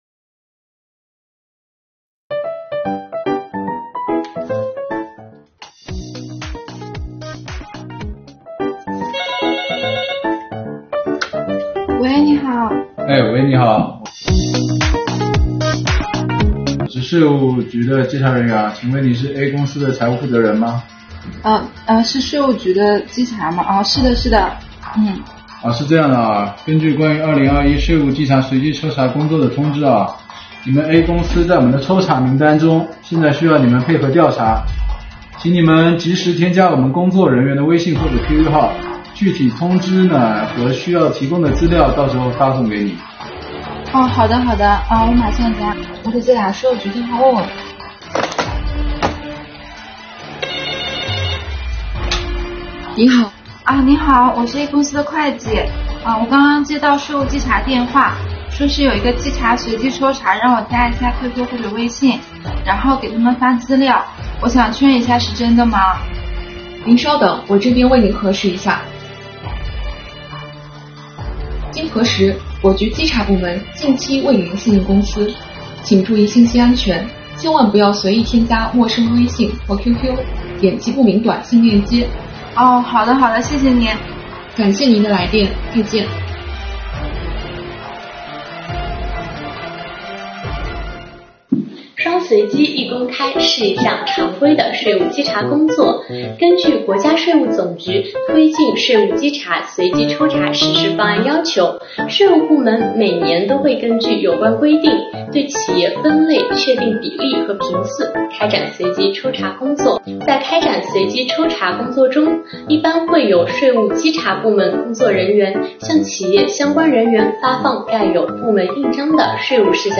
税务小姐姐带你一起识破最新税务稽查骗局~